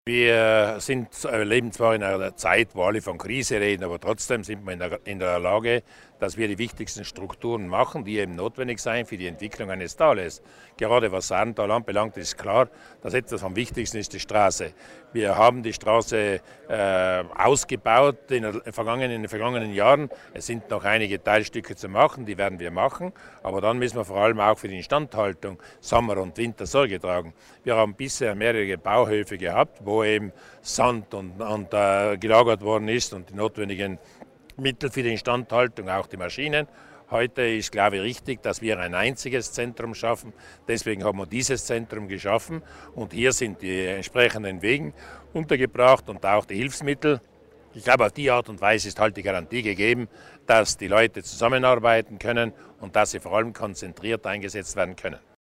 Landesrat Mussner über die Aufgaben im Straßenbau